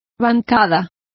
Complete with pronunciation of the translation of thwart.